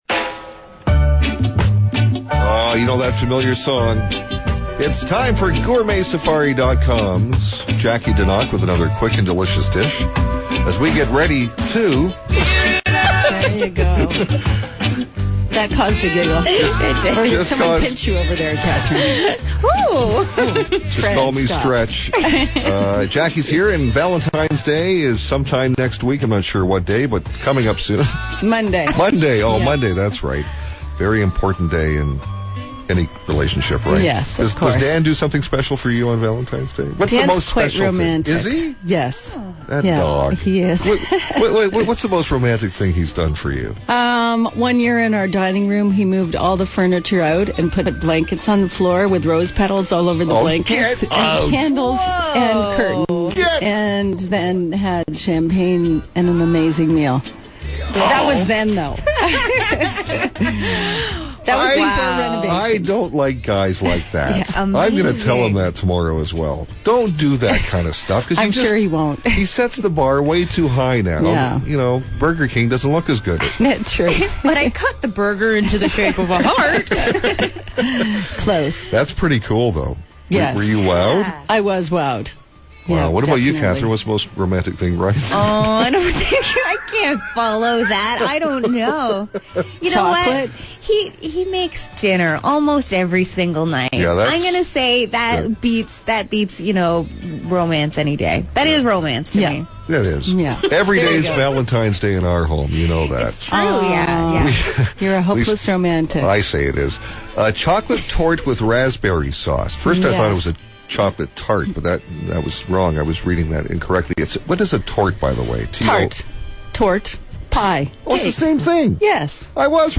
on 980 KRUZ in the morning talking Chocolate Torte